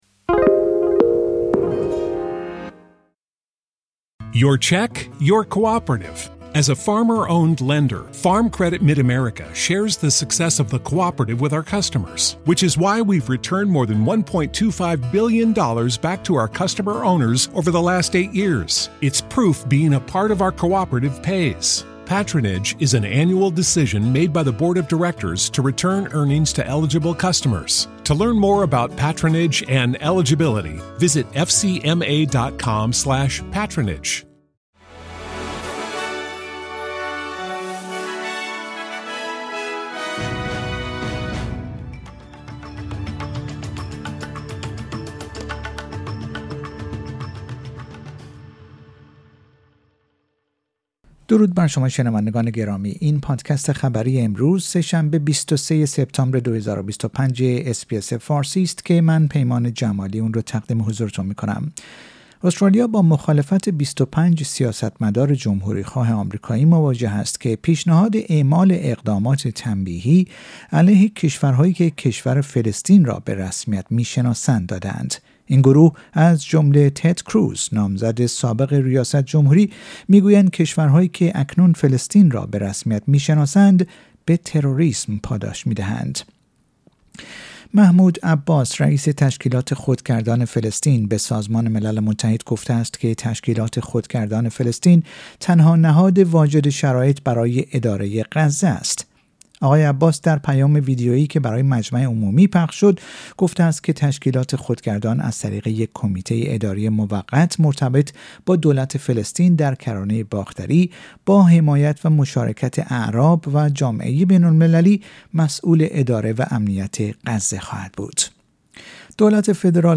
در این پادکست خبری مهمترین اخبار روز پنج شنبه ۲۵ سپتامبر ارائه شده است.